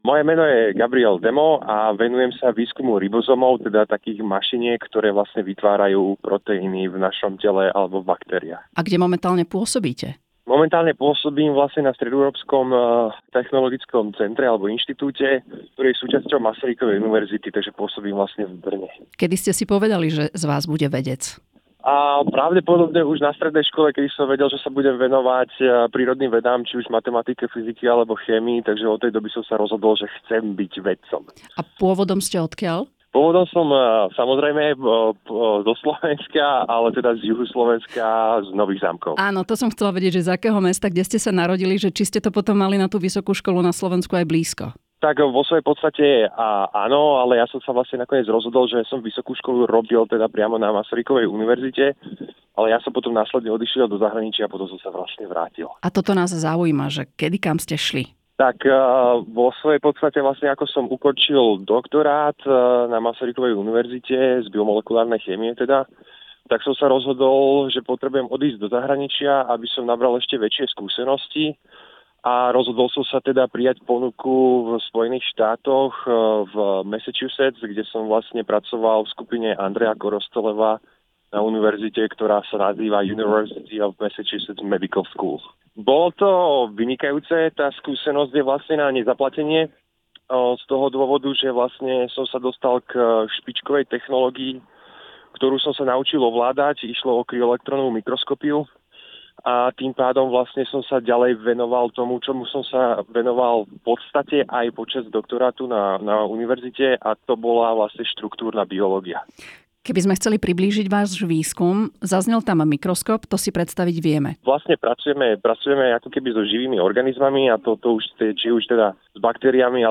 Žijemvedu v spolupráci s Rádiom Slovensko predstavuje slovenské vedkyne a vedcov v krátkych spotoch.
Všetky doterajšie rozhovory so súhlasom Rádia Slovensko priebežne uverejňujeme na našich stránkach.